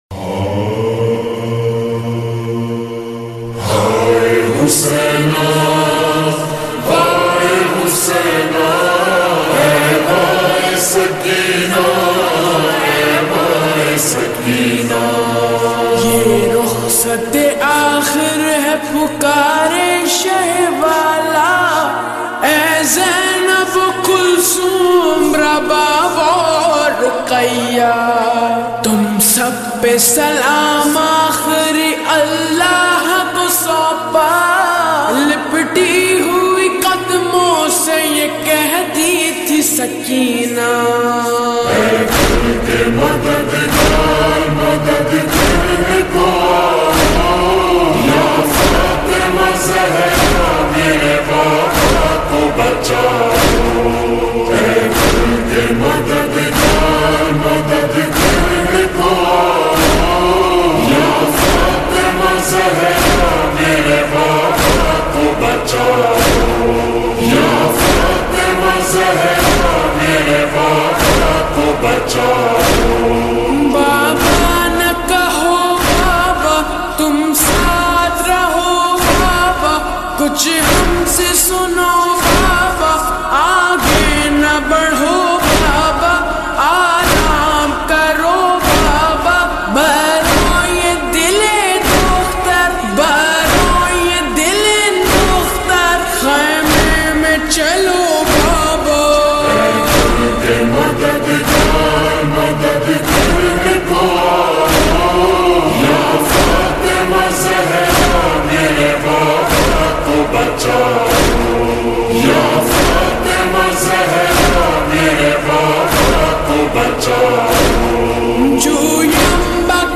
SAD NOHA